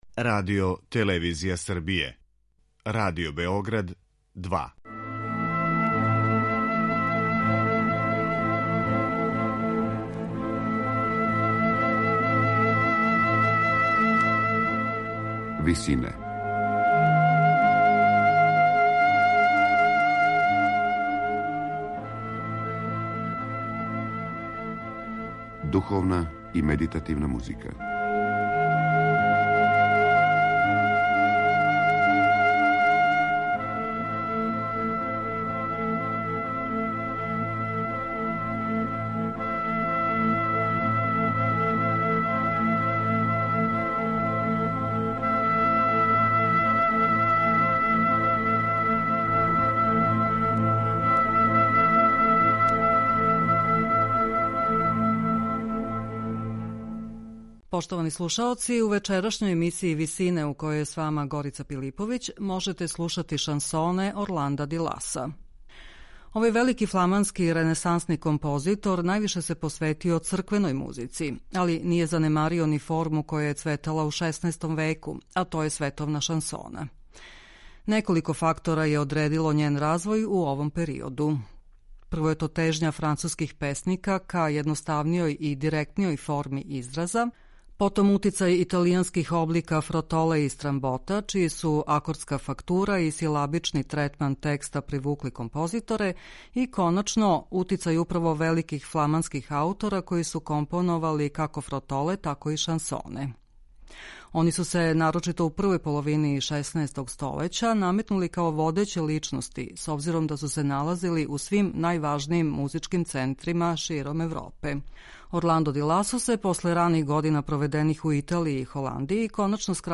Шансоне Орланда ди Ласа